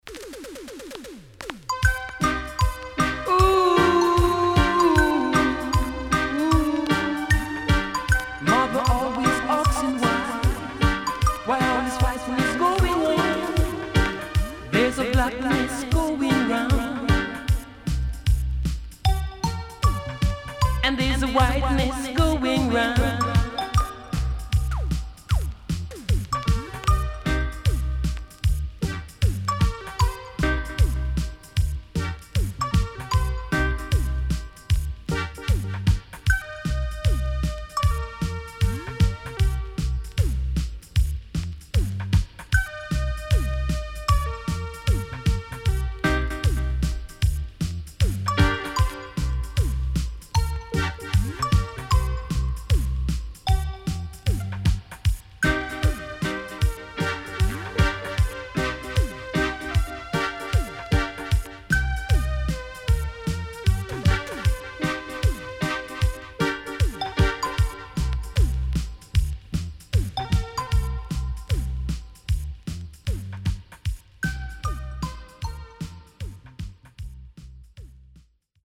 HOME > DANCEHALL
Digital Roots Vocal & Dubwise
SIDE A:所々ノイズ入ります。